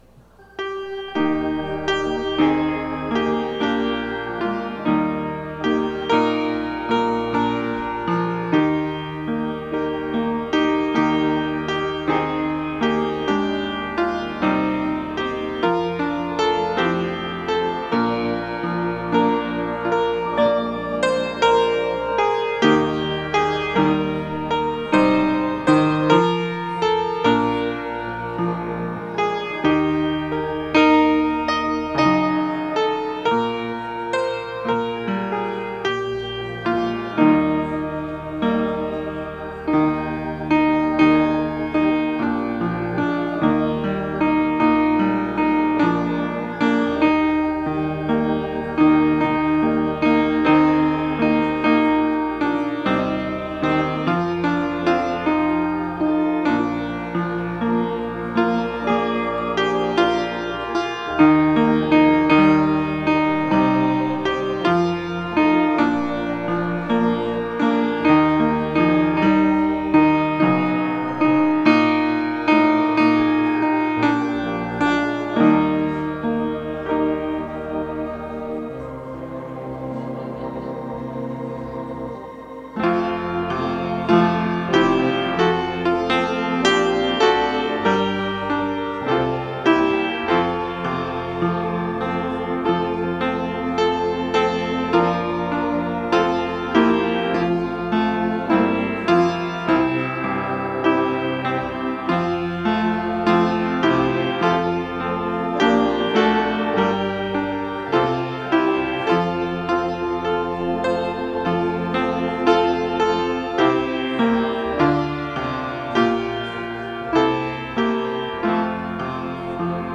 Weekly Sermons - Evangelical Free Church of Windsor, CO